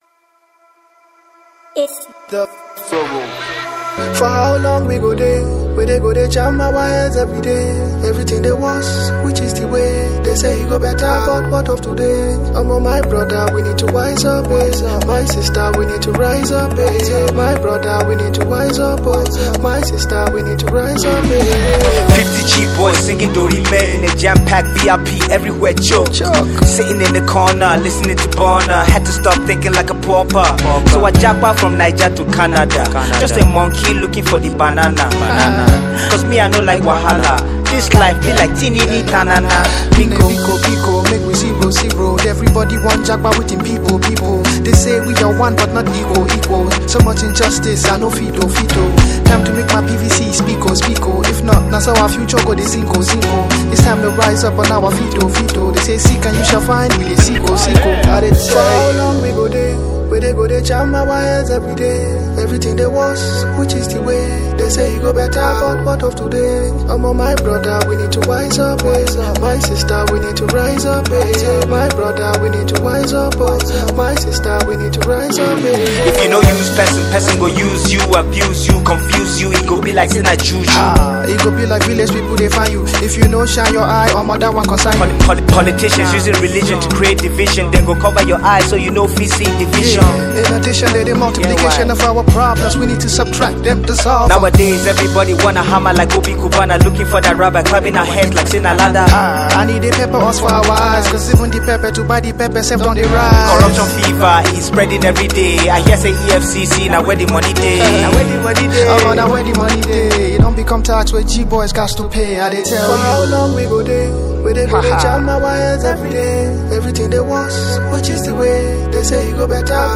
One of Nigeria’s Rapper.